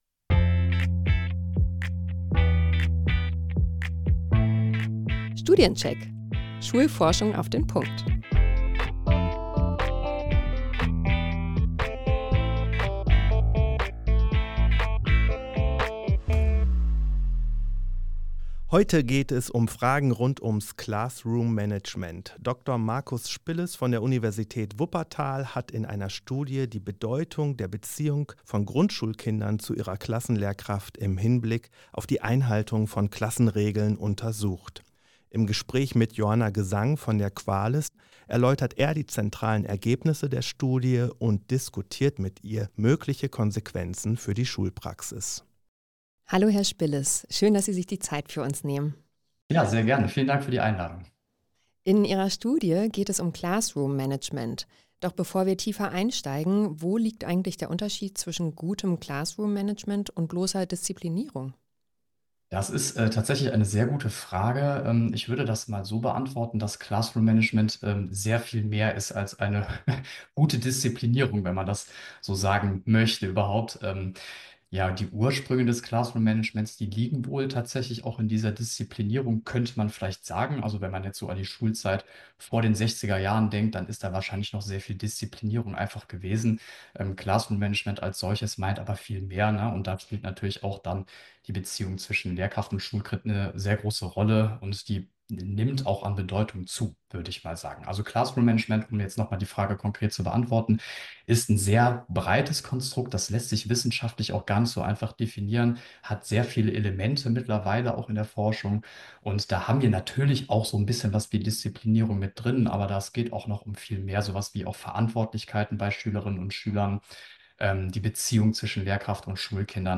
Für QUA-LiS NRW führen das Gespräch